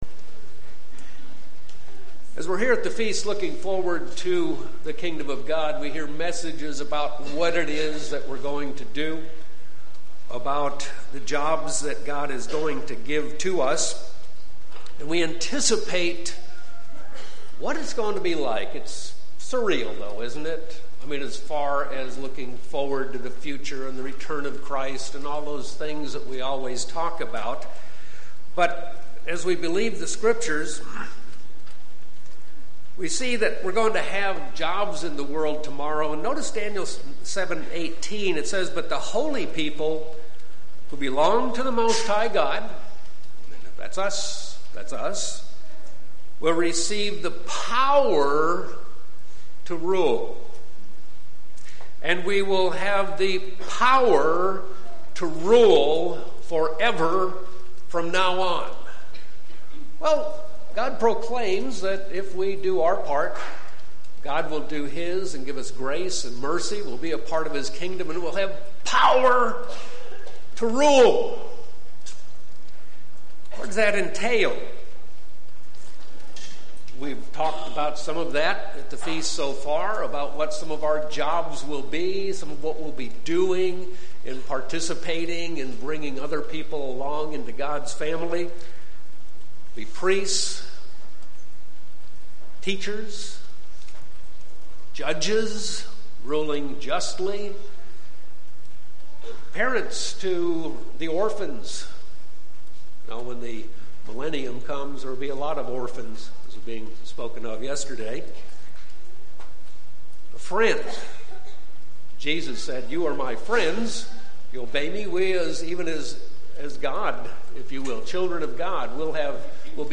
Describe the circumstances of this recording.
This sermon was given at the Anchorage, Alaska 2018 Feast site.